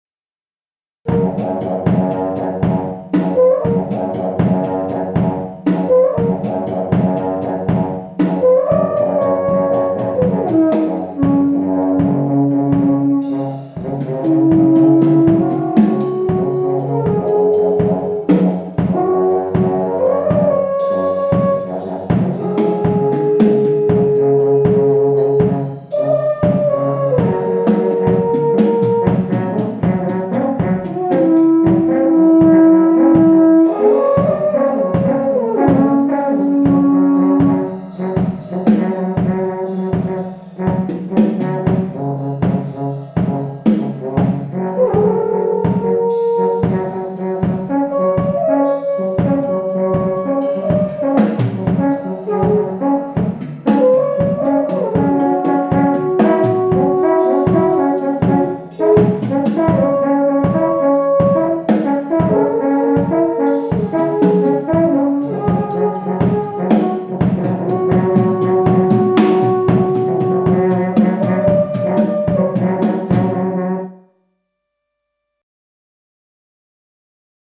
Musikalische Leitung: 2 Alphörner, 1 Schlagzeug, 1 Posaune